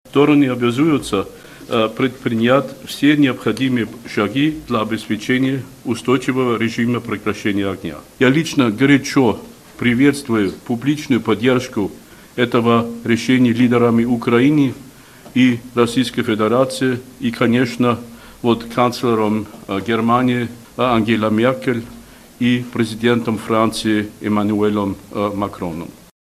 Контактная группа по Украине согласовала "школьное перемирие" с 25 августа. Это сообщил спецпредставитель ОБСЕ в трехсторонней контактной группе Мартин Сайдик.